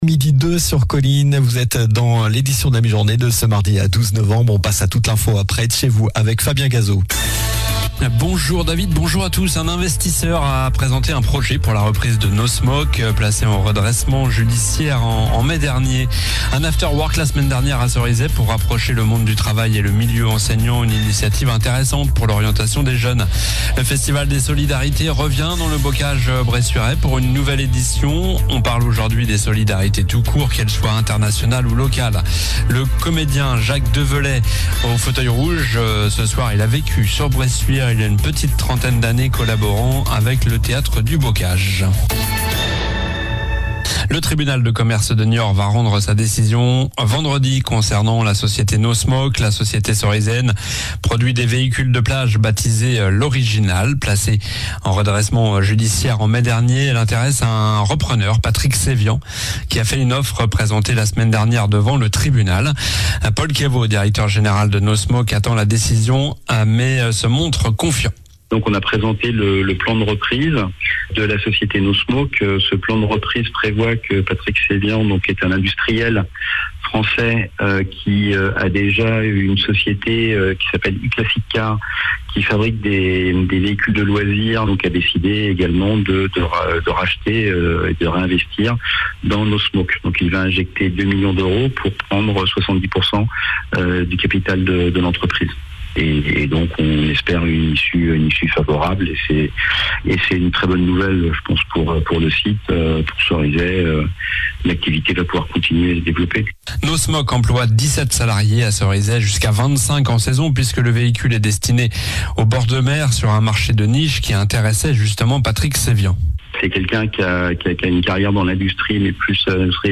Journal du mardi 12 novembre (midi)